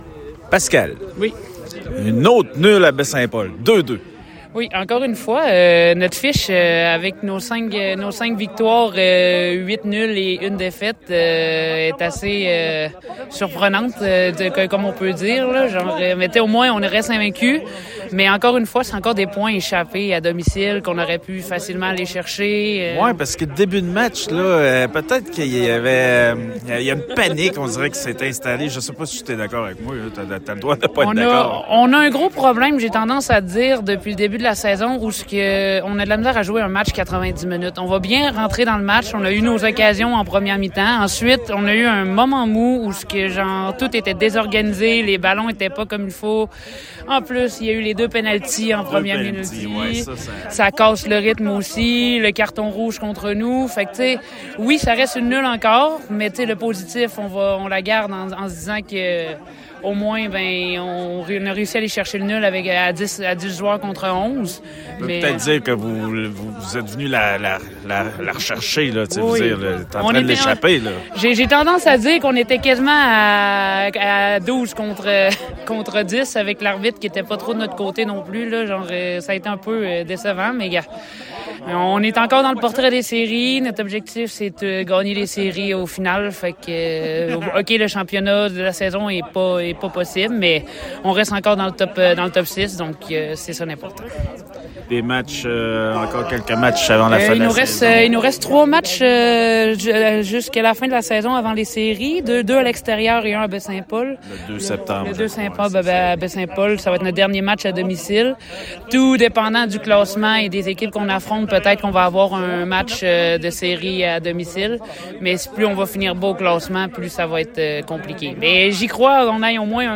Entrevue d’après match